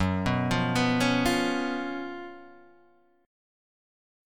F# Major 7th Suspended 4th